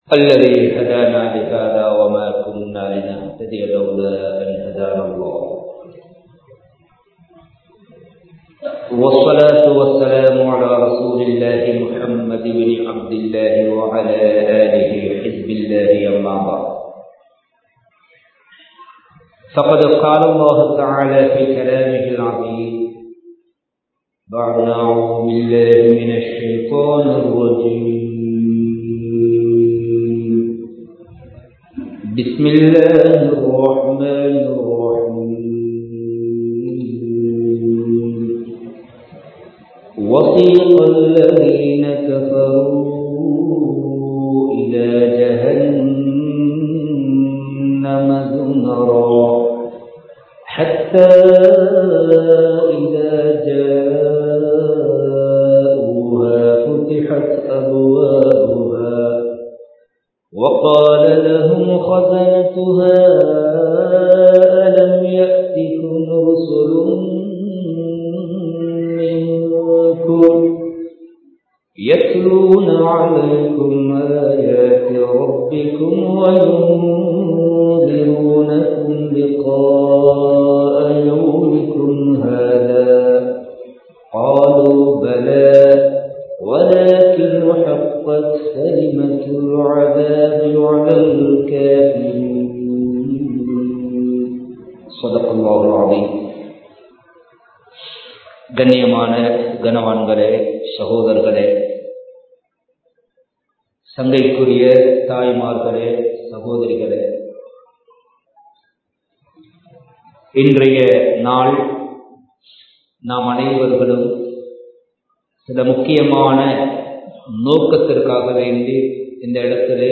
உண்மையான தௌபா | Audio Bayans | All Ceylon Muslim Youth Community | Addalaichenai
Kandy, Kattukela Jumua Masjith